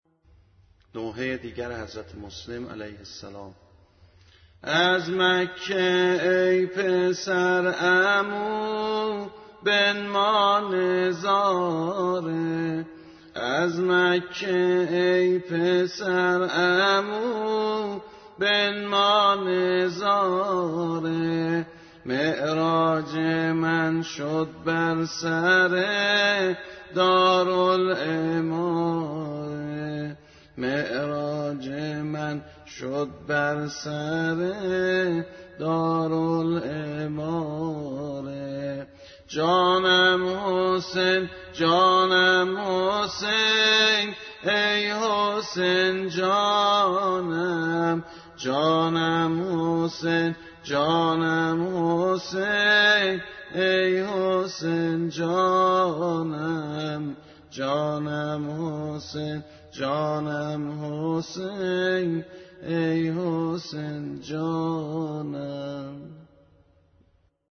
به همراه فایل صوتی سبک